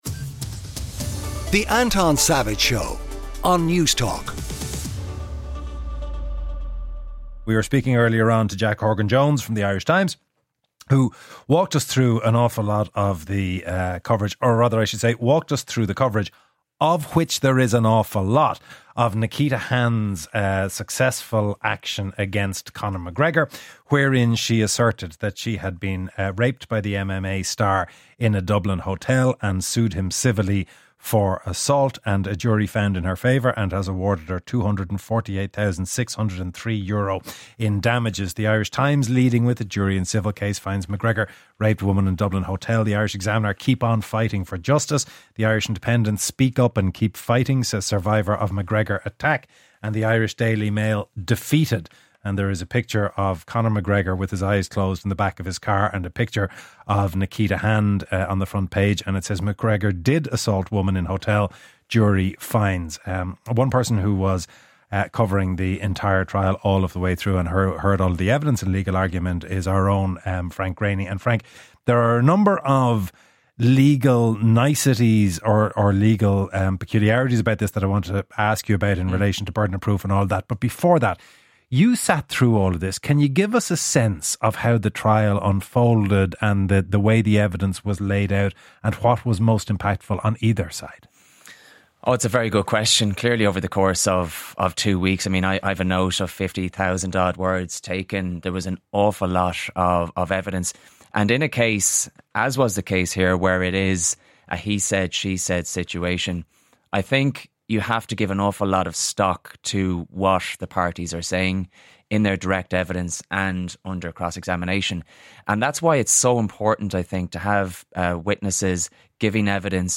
a bright, lively and engaging current affairs magazine show to kickstart your weekend. The programme features all the latest news, features and opinion along with conversations about the conversations you are having on a Saturday morning from 9-11am.